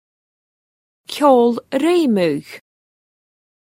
Amazon AWS (pronunciation [ Rather dubious! ; ws]).